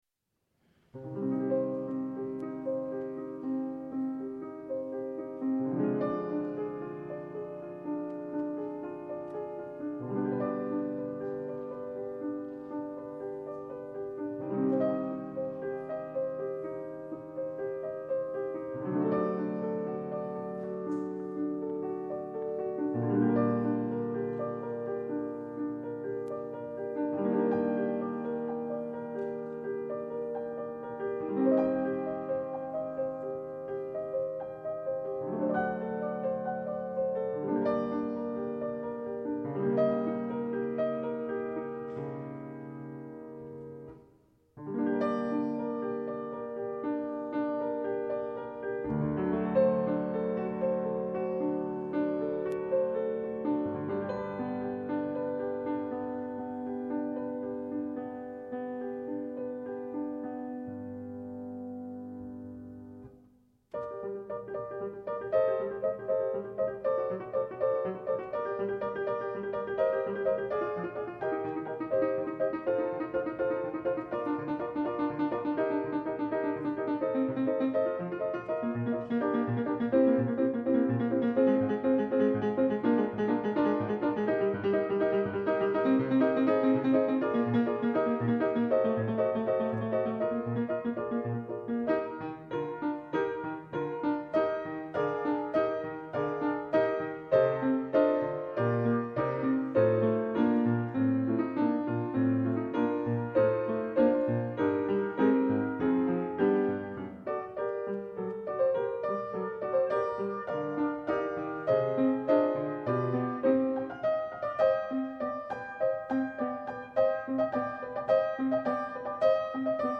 Τόσο ιδιαίτερη η βιόλα …ένας υπέροχος ήχος στο ημίφως θάρεις …και μέχρι κάποια περίοδο έμενε στις σκιές όντως μέχρι που συνθέτες όπως ο Paul Hindemith...